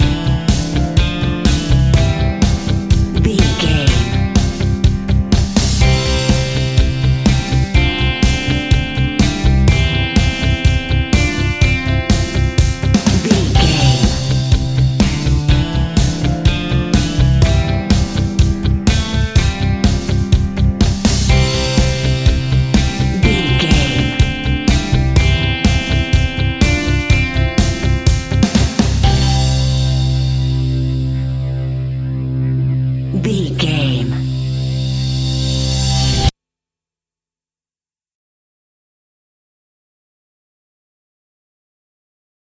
Fast paced
Uplifting
Ionian/Major
energetic
cheesy
instrumentals
indie pop rock music
guitars
bass
drums
piano
organ